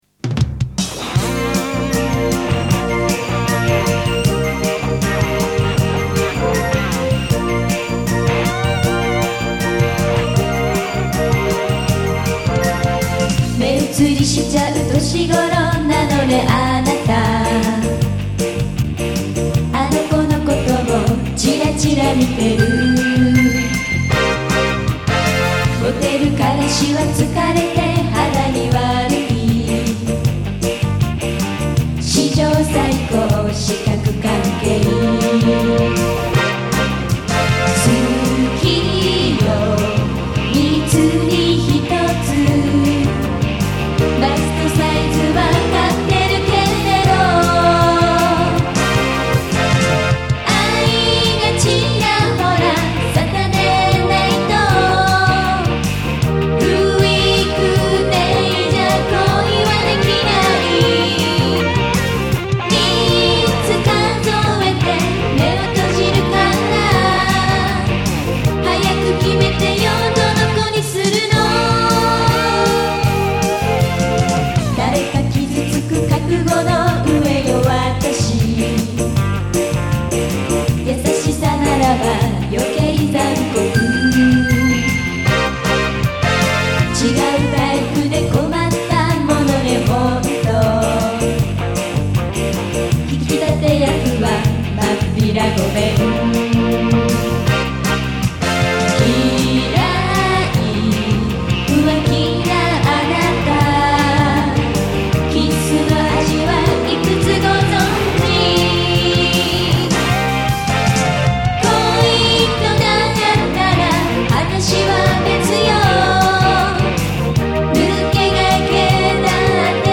MP3 (mono)